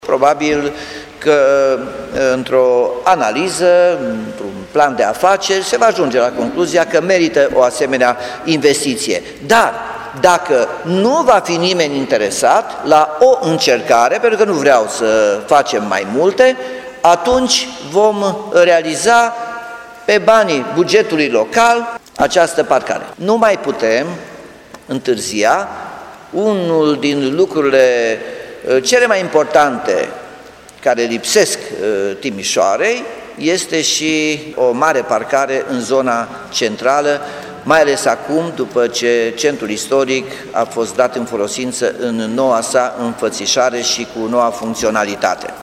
Potrivit primarului Nicolae Robu, intenţia este ca investiţia la parcare să fie realizată în parteneriat public- privat:
Nicolae-Robu-parcare-Oituz.mp3